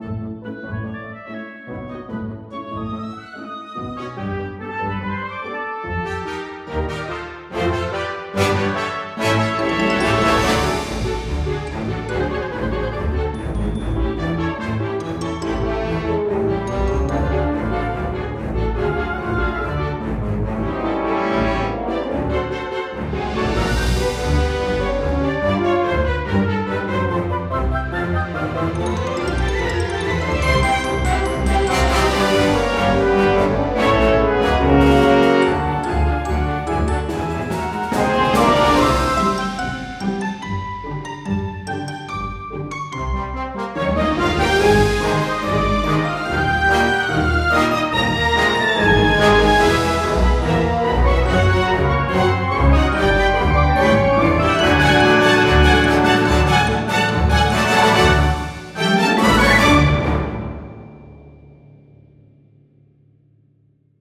Rainbow Fever Dream (Playful/Comedic)